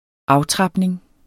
Udtale [ ˈɑwˌtʁɑbneŋ ]